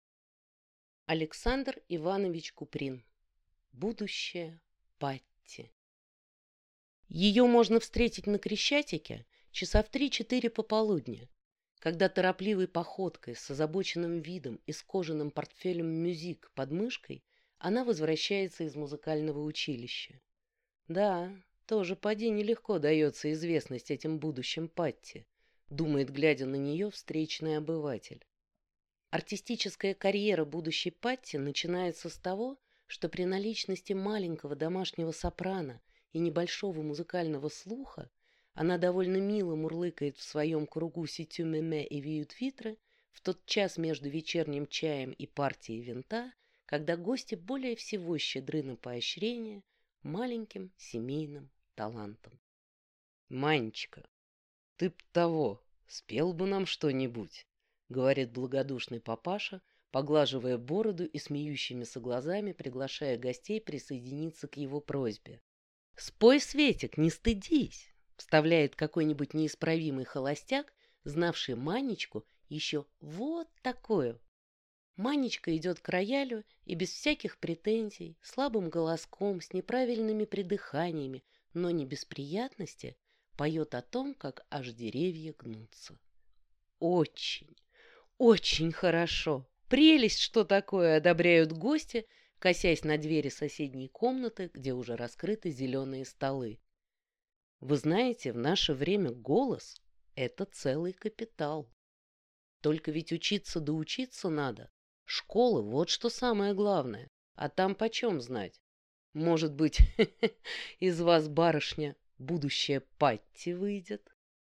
Аудиокнига Будущая Патти | Библиотека аудиокниг